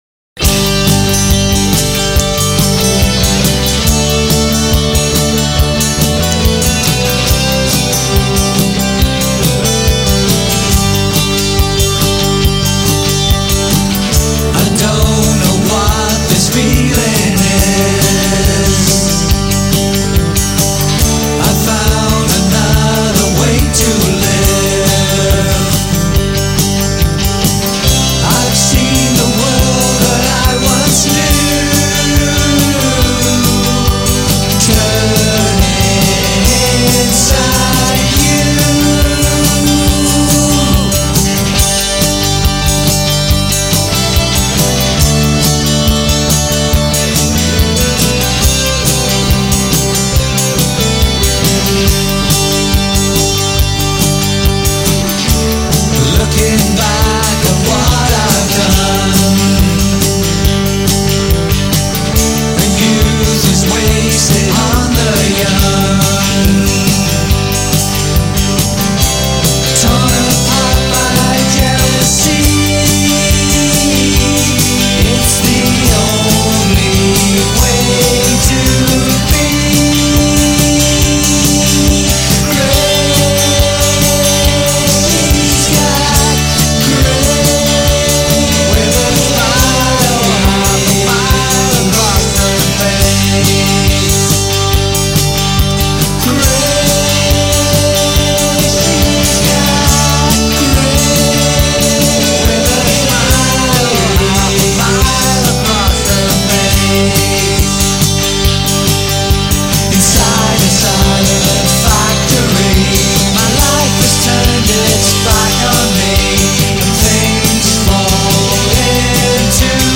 Mid 90s indie guitar US trio type number: Grace
Grateful for any thoughts on song structure, composition, amount of guitar parts, influences, mix, background harmonies etc. Aware of some bum vocal notes in there!